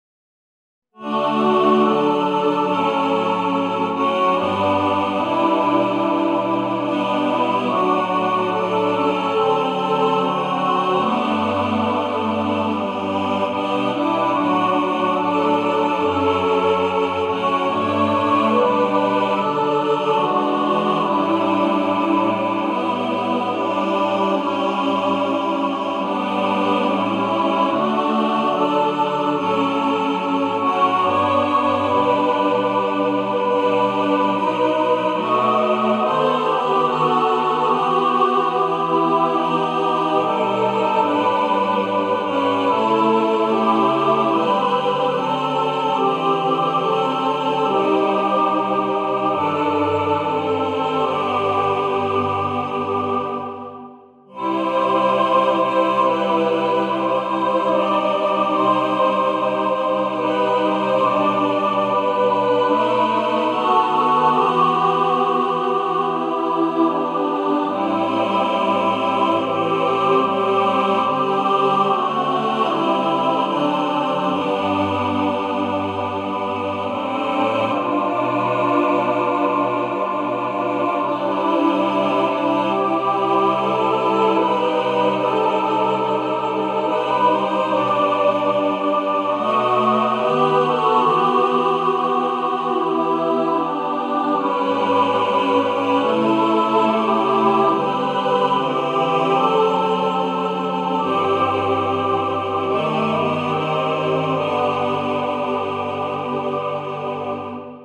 A beautiful Christmas lullaby hymn.